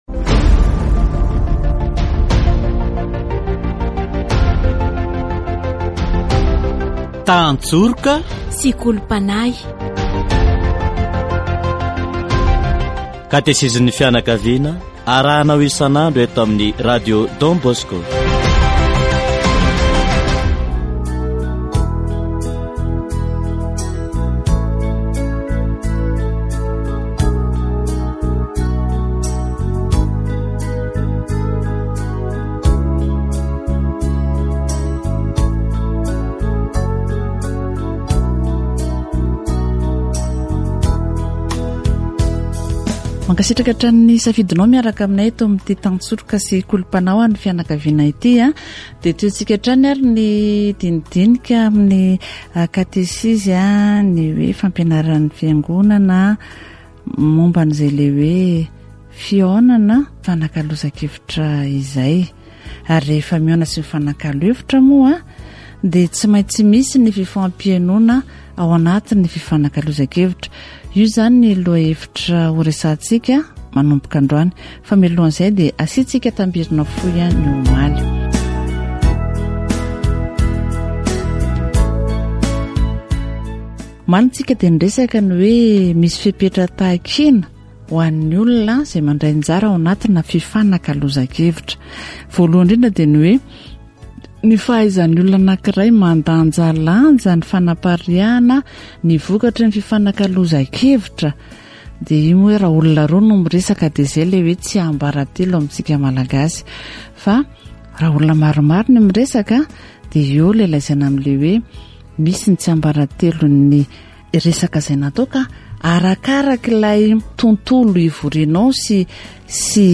Today's communication values have changed, one of the reasons is the different social networks. Catechesis on the exchange of ideas